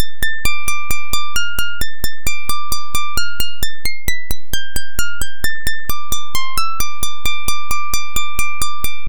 Channels: 2 (stereo)